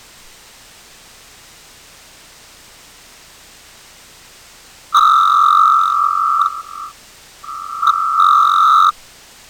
Descripción del sonido: Podemos escuchar cómo un sonido a una determinada frecuencia, (como explicamos en el ejemplo anterior) , se produce según barremos la imagen. Una onda de mayor amplitud  se irá atenuando hasta la mitad de la imagen, ya que el blanco (amplitud máxima), se irá acercando poco a poco al color negro (amplitud nula), pasando por una escala intermedia de grises.